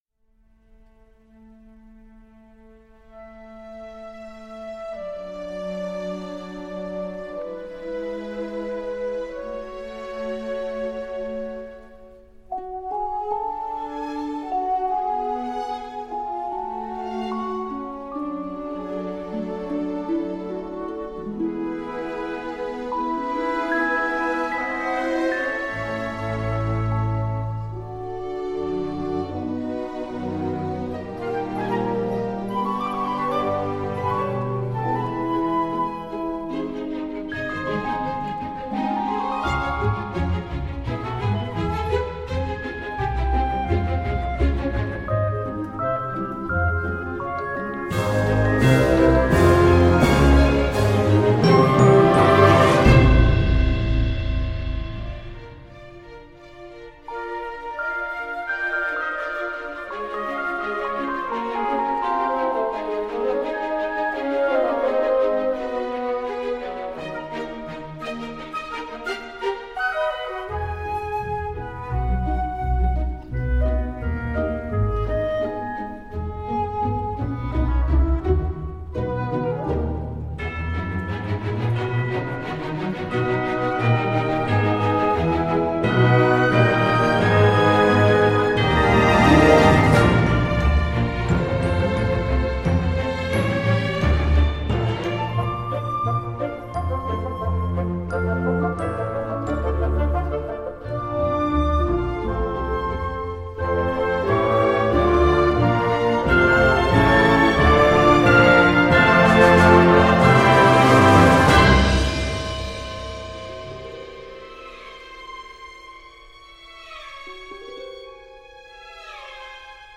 C’est magique, survolté, charmant et féerique.